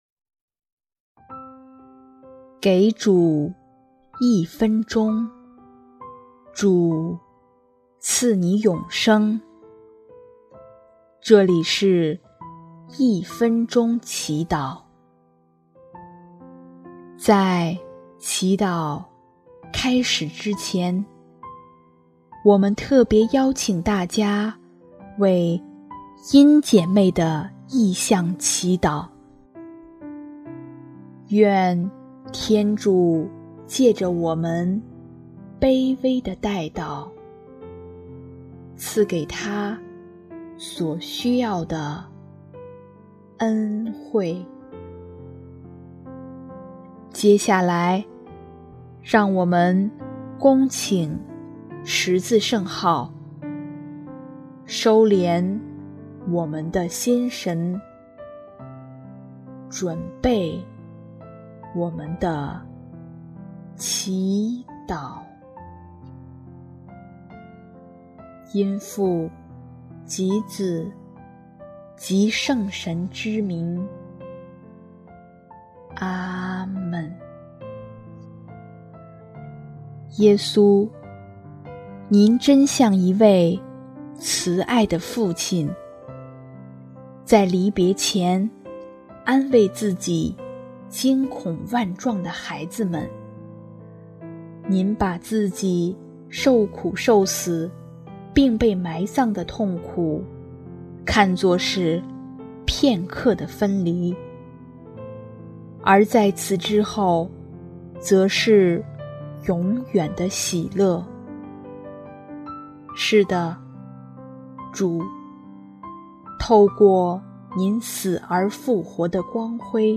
【一分钟祈祷】| 5月18日 将眼光注目永恒的欢乐
音乐 ：第三届华语圣歌大赛参赛歌曲《真爱致最爱》